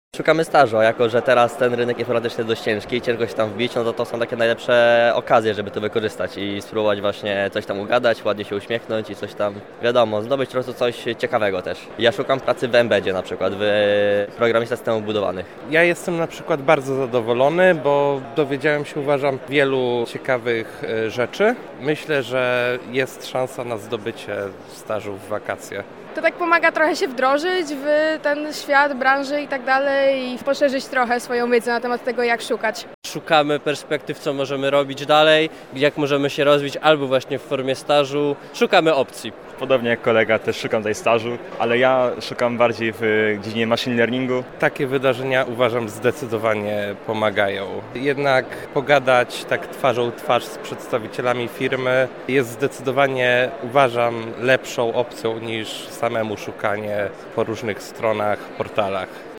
Jak podkreśliła przedstawicielka firmy zajmującej się produkcją oprogramowania, wiele osób zaczęło swoją przygodę zawodową od stażu i pracuje w tym miejscu do dziś.